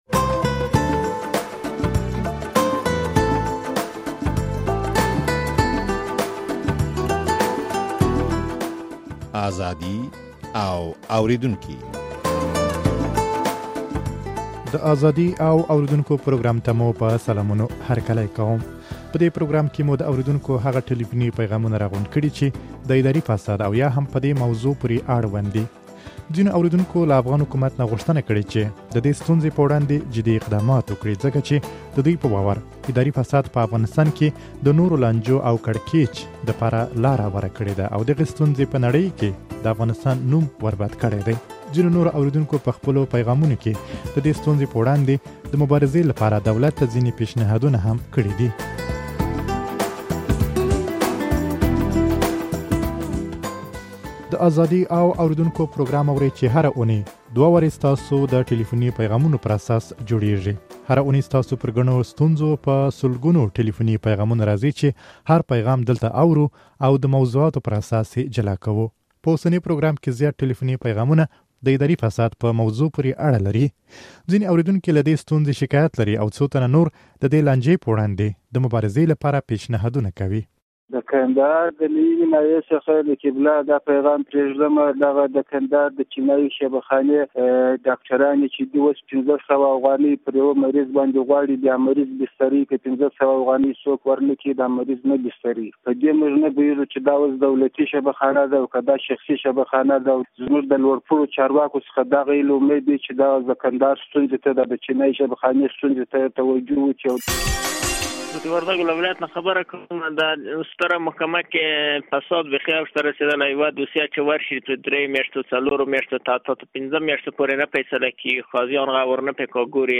په دې پروګرام کې مو د اورېدونکو هغه ټليفوني پيغامونه راغونډ کړي، چې د اداري فساد او يا هم په دې موضوع پورې اړوند دي.